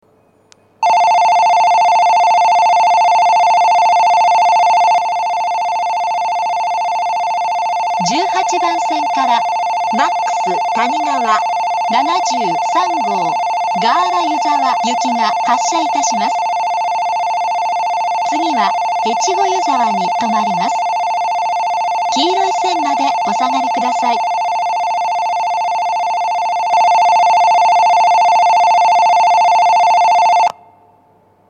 標準的な音程の発車ベルを使用していますが、１５・１６番線の発車ベルは音程が低いです。
１８番線発車ベル Ｍａｘたにがわ７３号ガーラ湯沢行の放送です。